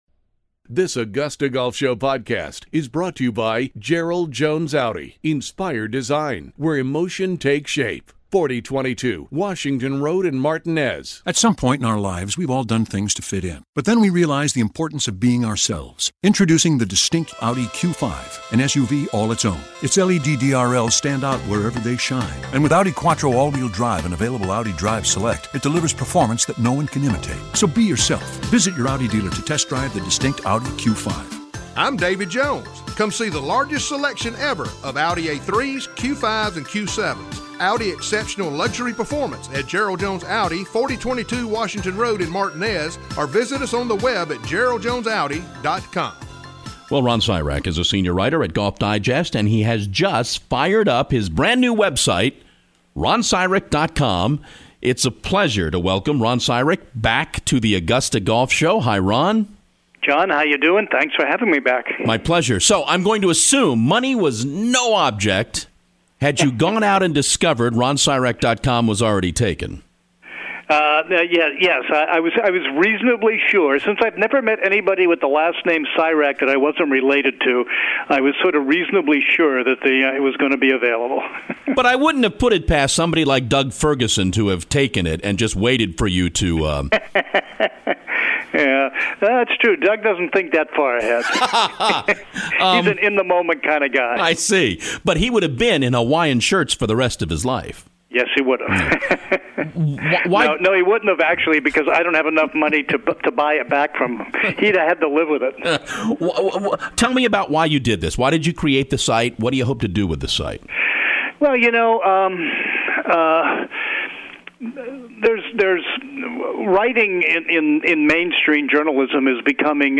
The AGS Interview